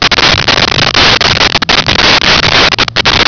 Sfx Amb Watertunnel Loop
sfx_amb_watertunnel_loop.wav